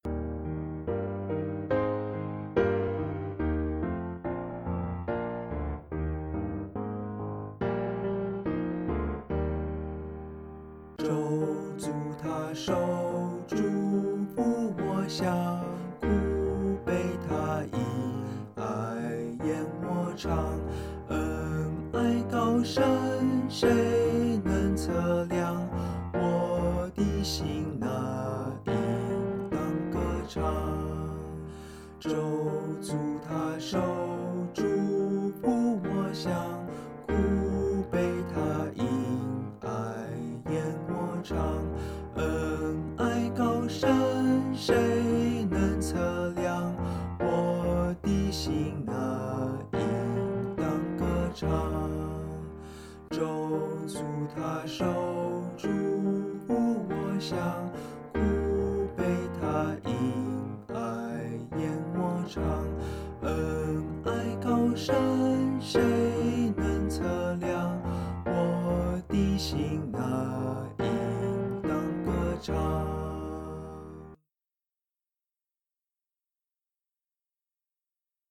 ch_0183_vocal.mp3